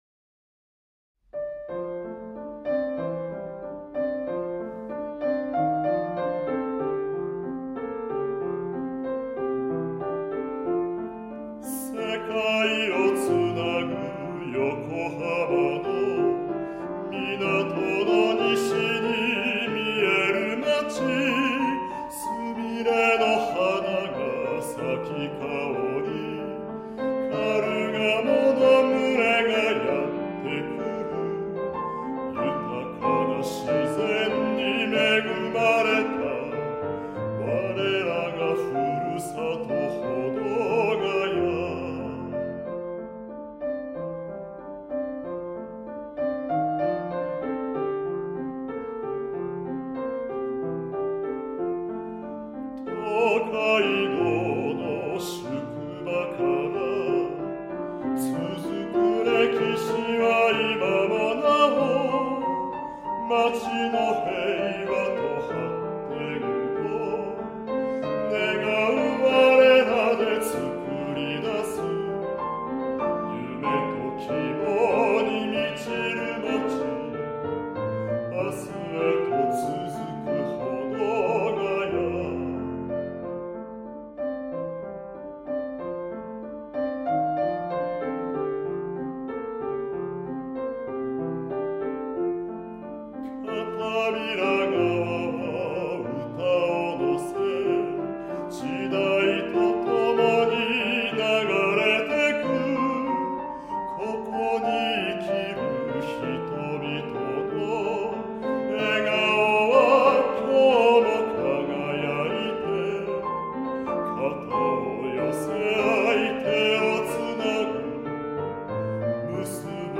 Canção da ala
solo de soprano